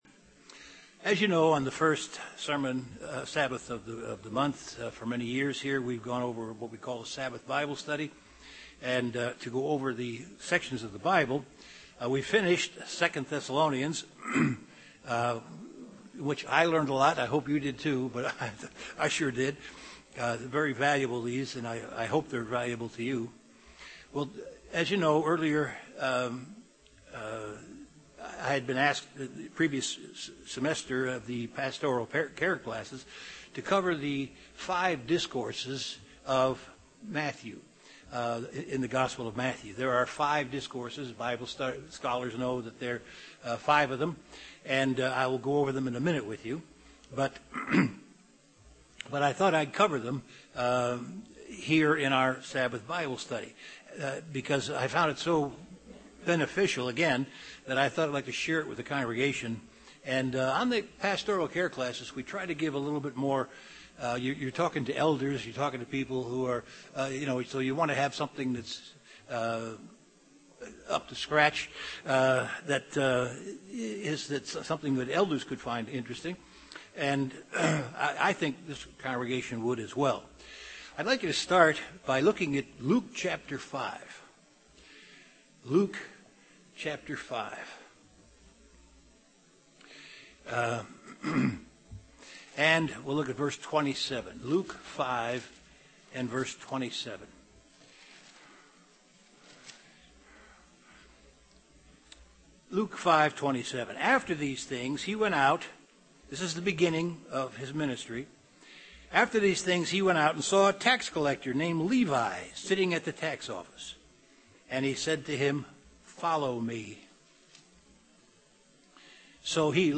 Given in Beloit, WI
UCG Sermon